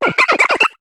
Cri d'Otaquin dans Pokémon HOME.